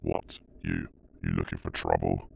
Voice Lines
Update Voice Overs for Amplification & Normalisation
what what are you looking for trouble.wav